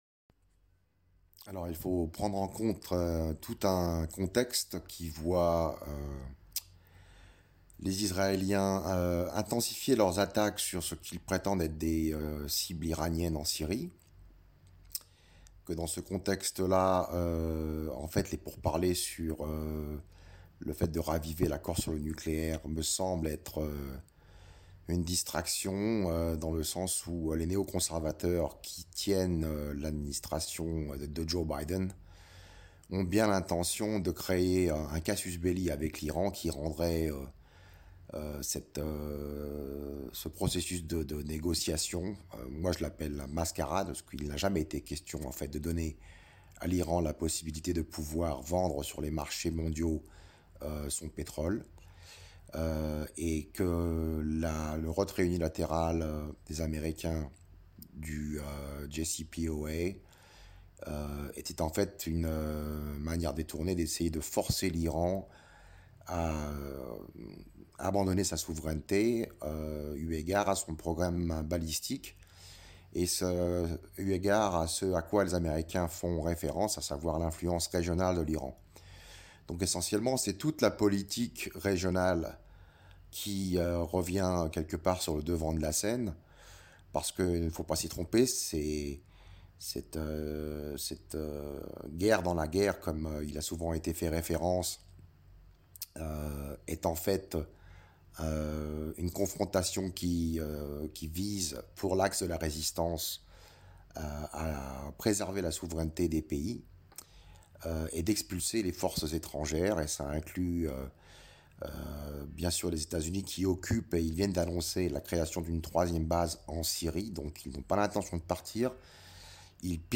Mots clés Syrie usa interview Eléments connexes Iran à Genève : l’entité sioniste menace la sécurité mondiale / Des missiles yéménites sur Tel-A